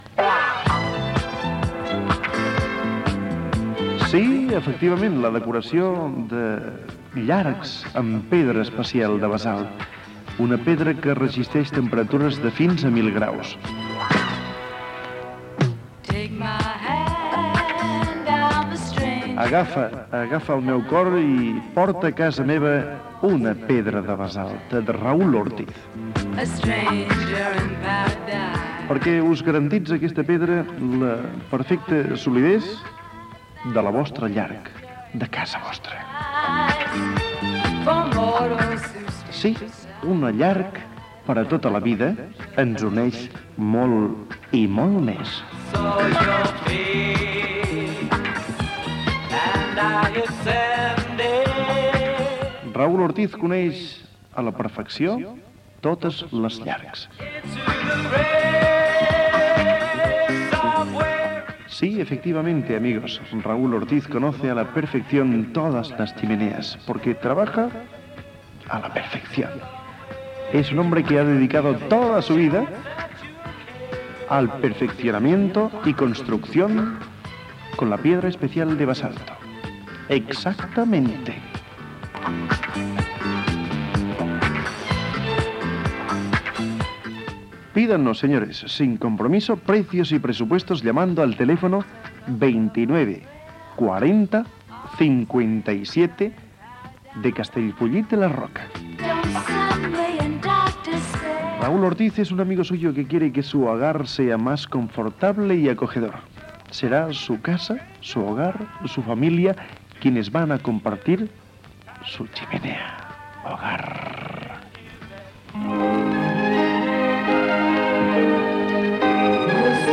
Comentari sobre la feina dels locutors de ràdio Gènere radiofònic Publicitat